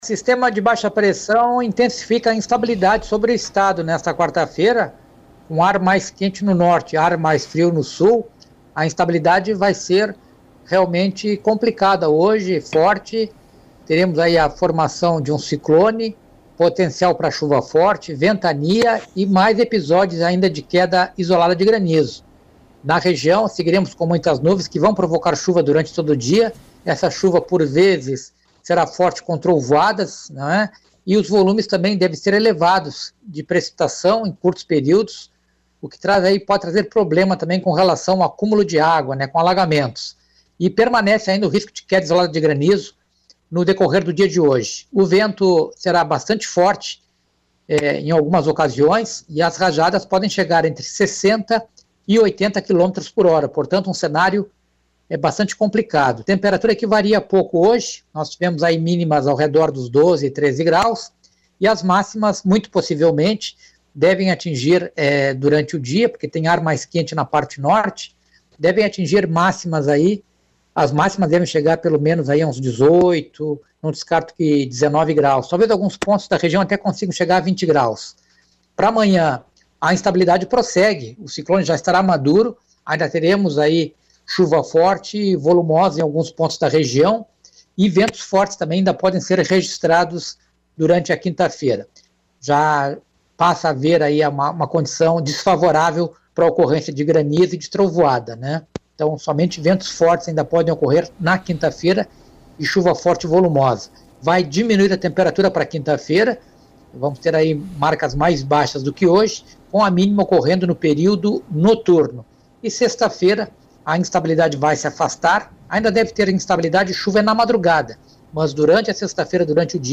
PREVISAO_DO_TEMPO.mp3